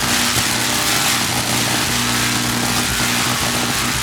electric_sparks_lightning_loop3.wav